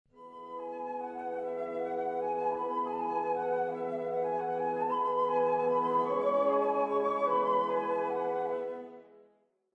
Ascolta questa melodia di E. Grieg e completa la frase con le note mancanti, come indicato nel foglio di lavoro.